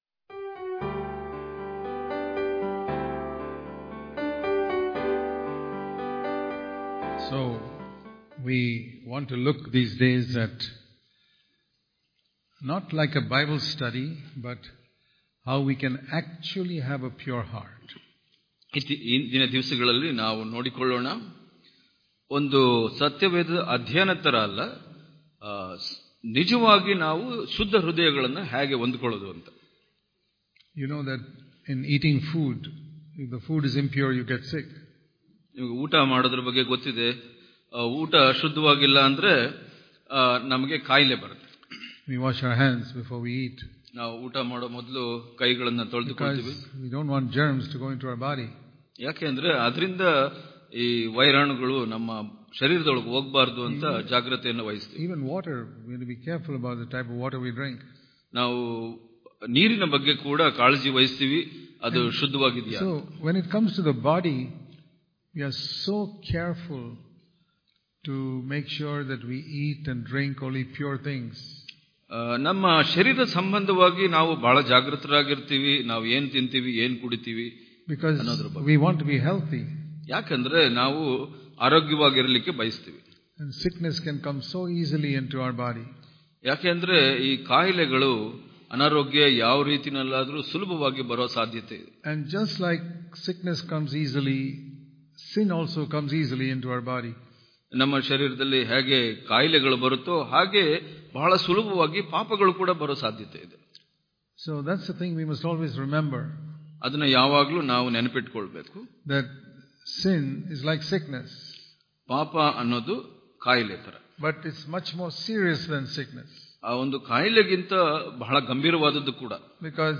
March 24 | Kannada Daily Devotion | This Life On Earth Is A Testing Period For Your Eternal Life Daily Devotions